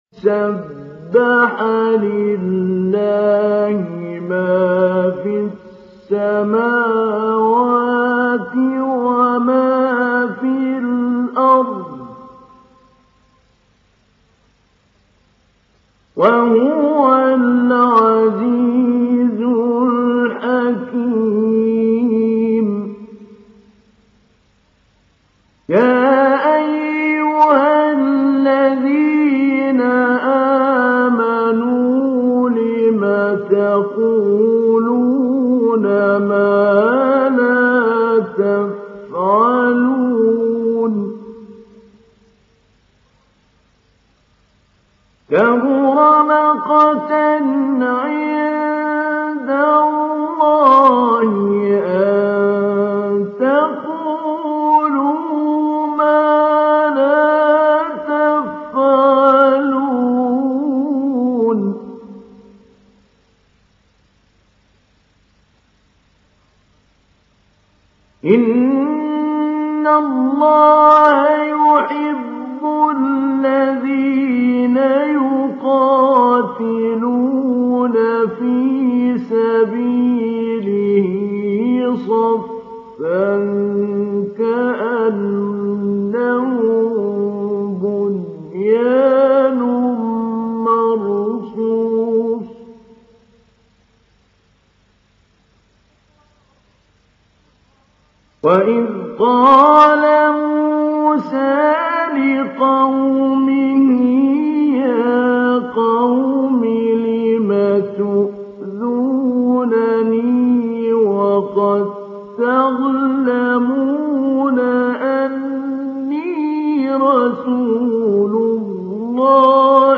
সূরা আস-সাফ ডাউনলোড mp3 Mahmoud Ali Albanna Mujawwad উপন্যাস Hafs থেকে Asim, ডাউনলোড করুন এবং কুরআন শুনুন mp3 সম্পূর্ণ সরাসরি লিঙ্ক